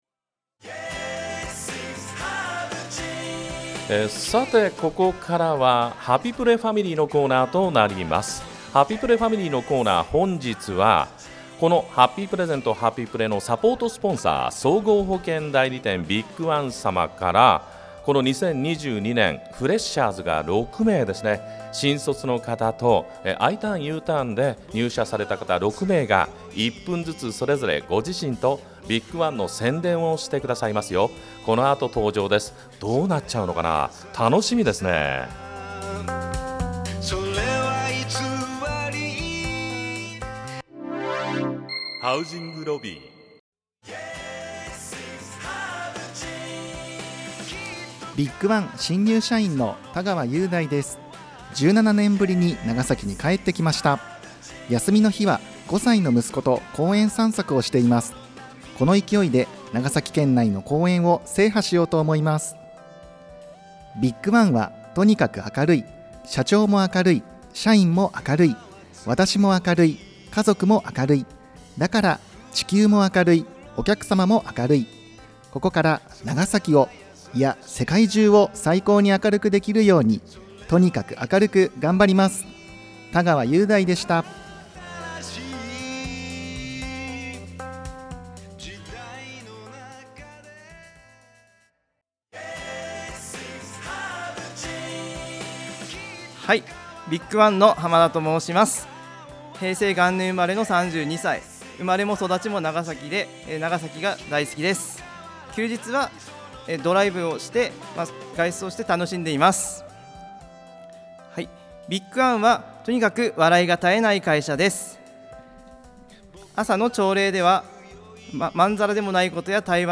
ラジオCM収録に行ってきました！！
一人1分間、前半は自己紹介、後半はビッグ・ワンの紹介で、
緊張も伝わってきましたが、